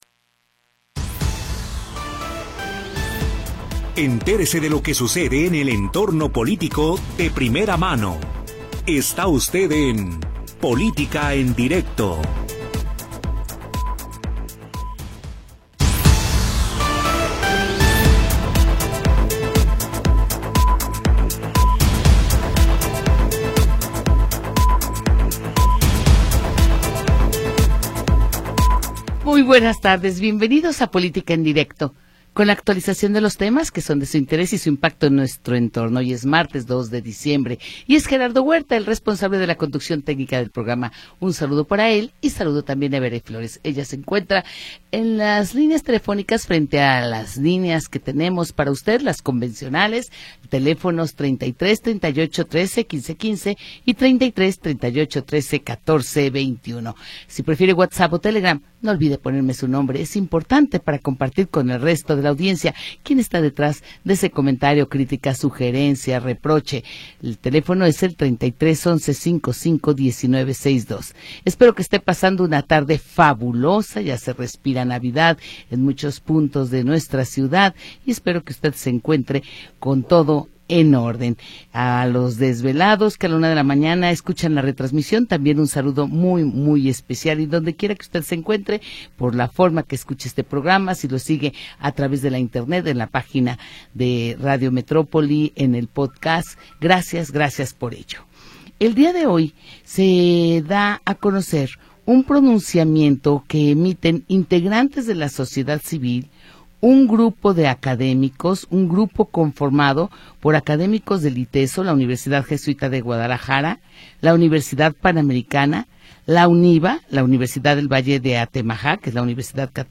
Programa transmitido el 2 de Diciembre de 2025.